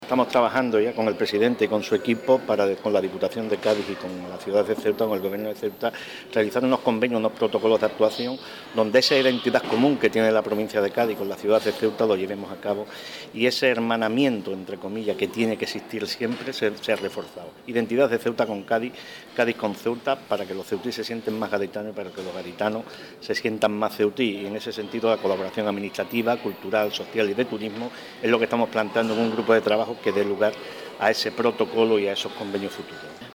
José Loaiza informa sobre las líneas de trabajo